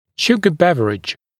[‘ʃugə ‘bevərɪʤ][‘шугэ ‘бэвэридж]напиток с содержанием сахара